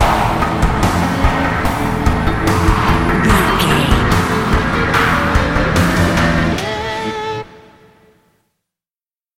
In-crescendo
Thriller
Aeolian/Minor
synthesiser
drum machine
electric guitar